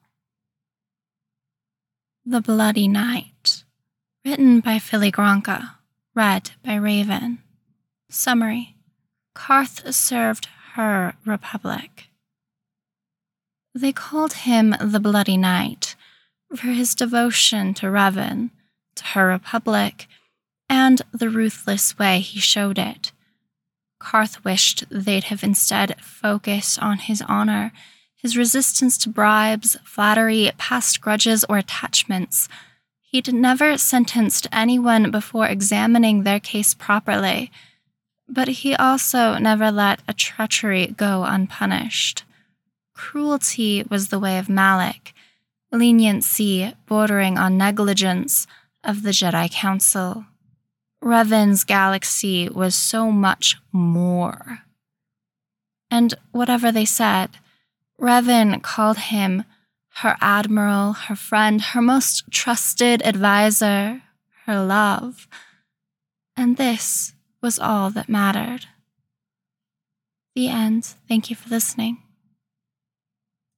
with soundscaping: download mp3: here (r-click or press, and 'save link') [19 MB, 00:18:44]